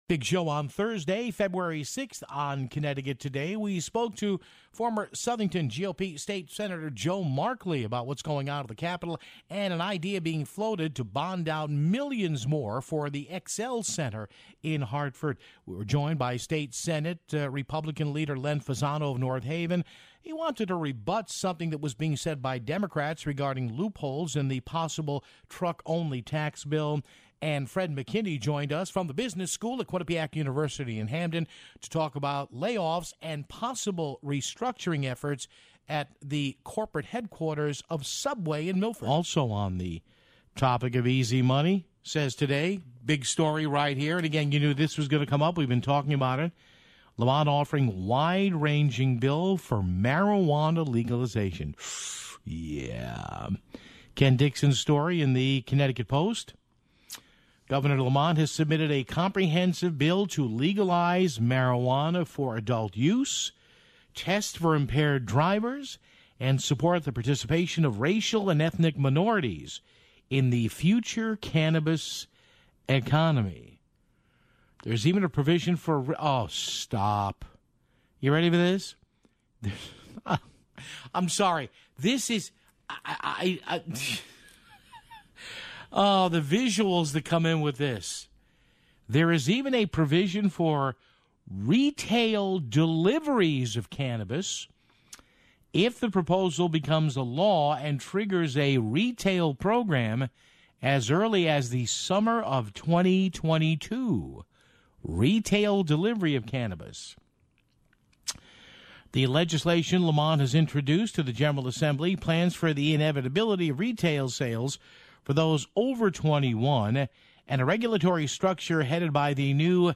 Joe Markley called in to talk about how the Governor is bonding $55 million to renovate the XL Center. State Senate President Pro Tempore Len Fasano called in to talk about a claim his fellow legislator made about Fasano's facts.